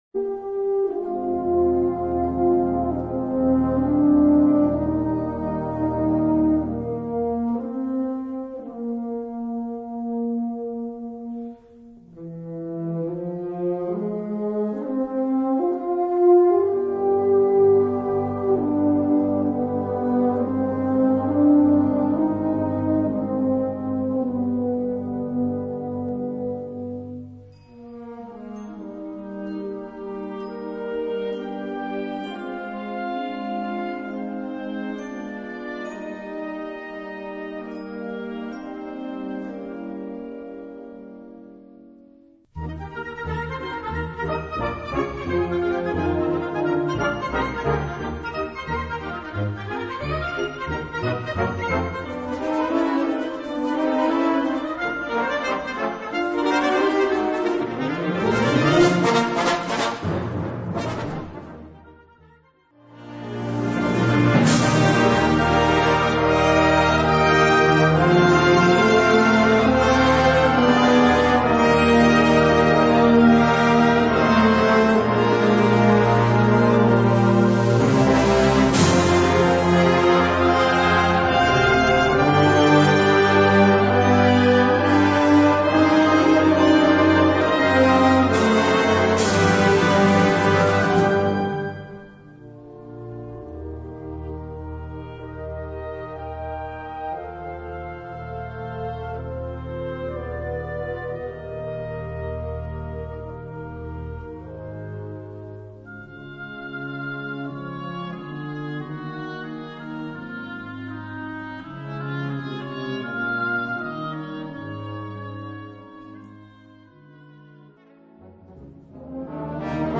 Kategorie Blasorchester/HaFaBra
Unterkategorie Zeitgenössische Bläsermusik (1945-heute)
Besetzung Ha (Blasorchester)